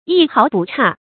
一毫不差 注音： ㄧ ㄏㄠˊ ㄅㄨˋ ㄔㄚˋ 讀音讀法： 意思解釋： 完全相同，沒有一點差異。